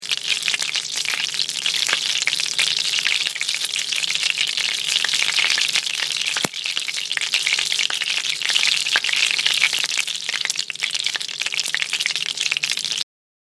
That “sizzle” is a combination of water hissing into vapor and flavorful proteins and salts frying in the fat.
Our pan of butter will hit a crescendo, if you will, of bubbling fatty brown goodness, then start to die away.
click here to hear an MP3 of the change in sizzle, at the 10s mark).
butter-browning.mp3